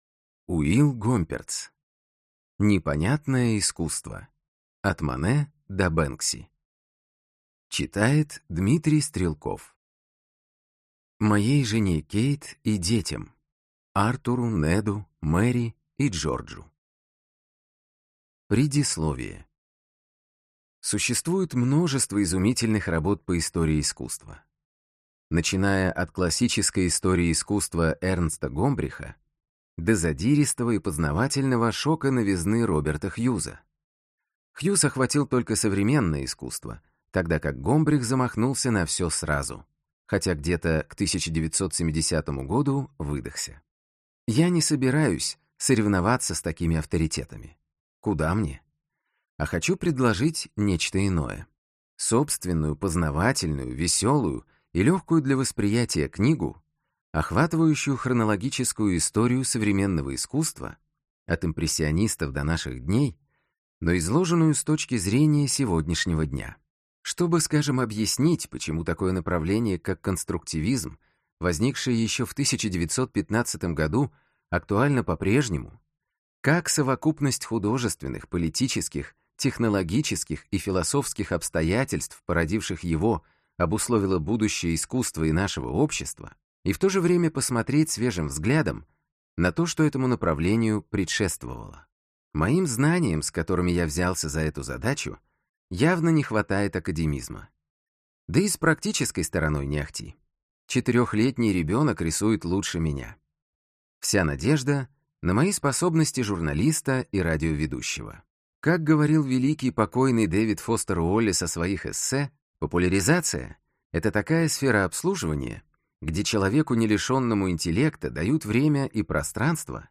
Аудиокнига Непонятное искусство. От Моне до Бэнкси | Библиотека аудиокниг